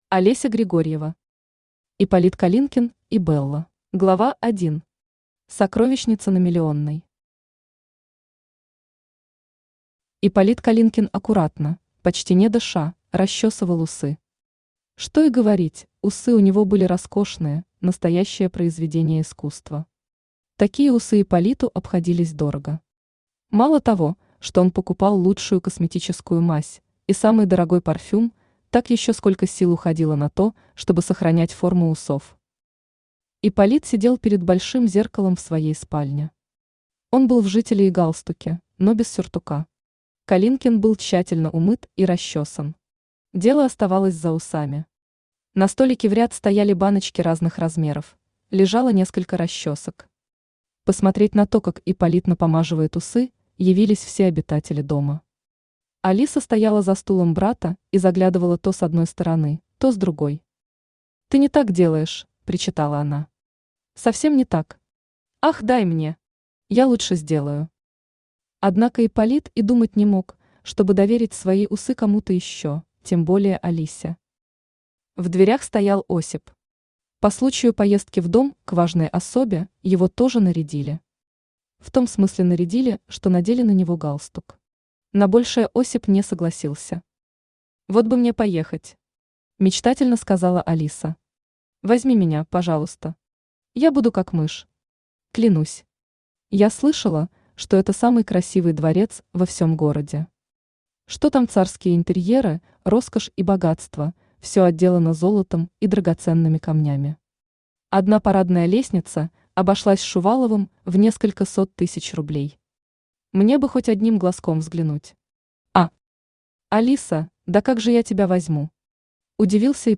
Аудиокнига Ипполит Калинкин и Белла | Библиотека аудиокниг
Aудиокнига Ипполит Калинкин и Белла Автор Олеся Григорьева Читает аудиокнигу Авточтец ЛитРес.